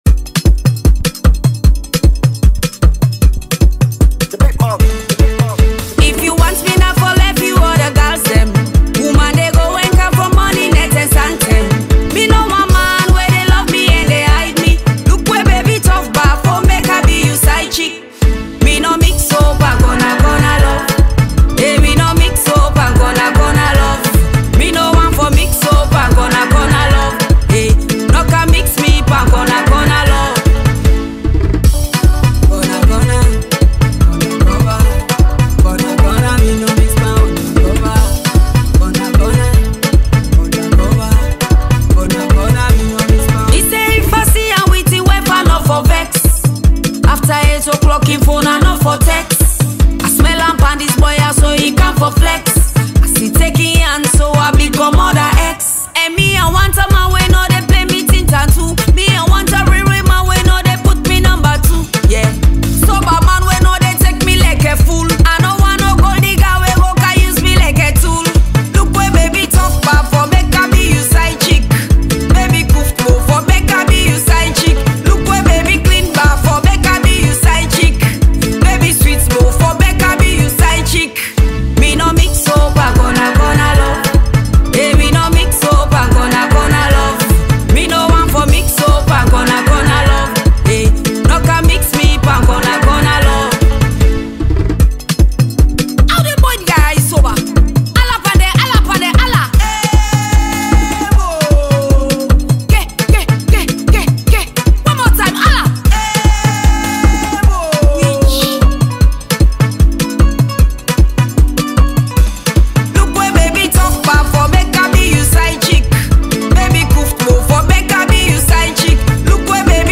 Multi-award-winning hip-hop and dancehall artist
street anthem